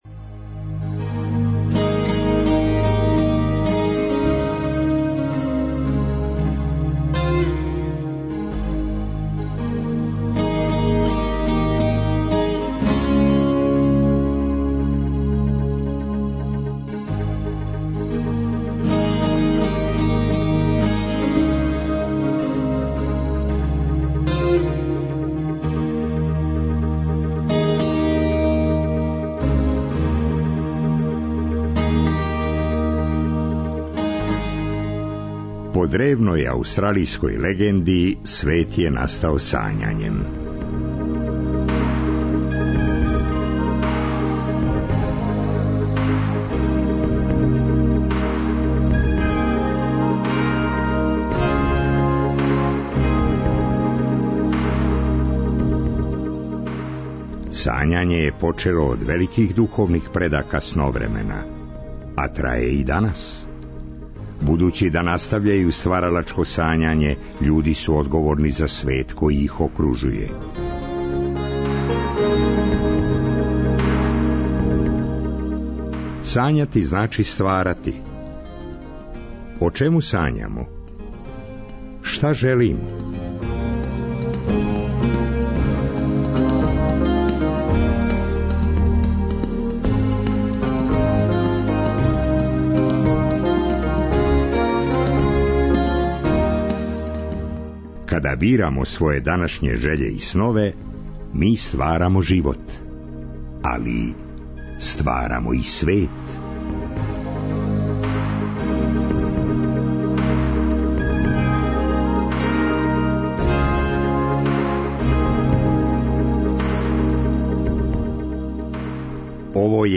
са слушаоцима ће бити водитељи и гости у студију